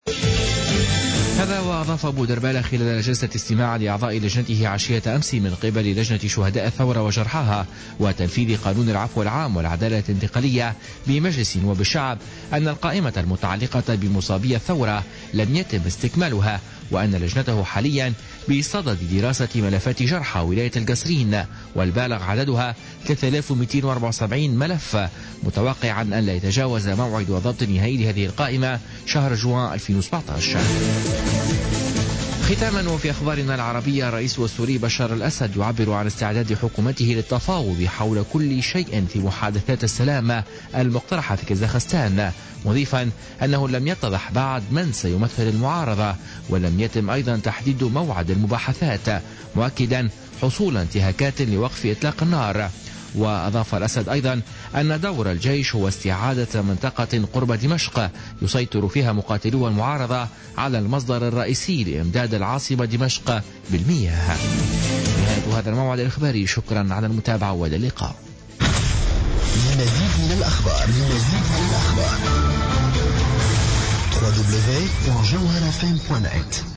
نشرة أخبار منتصف الليل ليوم الثلاثاء 10 جانفي 2017